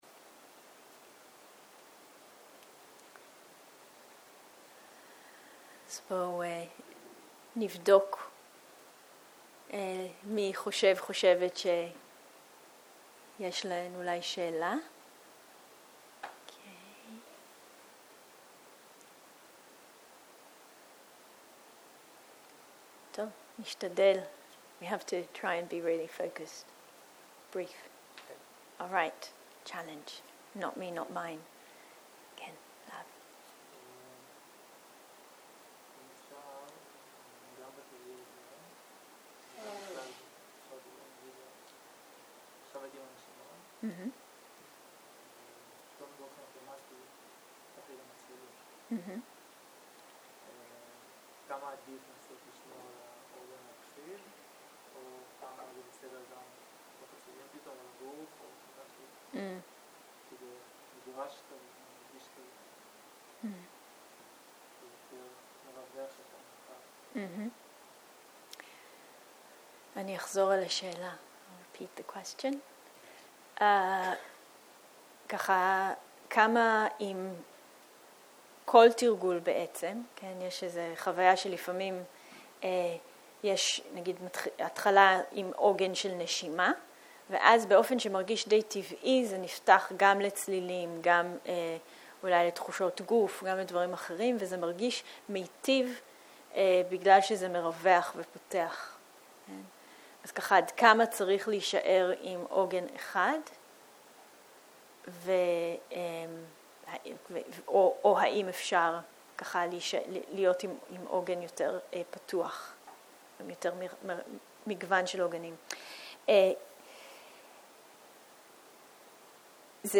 בוקר - שאלות ותשובות.
0:00 0:00 סוג ההקלטה: סוג ההקלטה: שאלות ותשובות שפת ההקלטה: שפת ההקלטה: עברית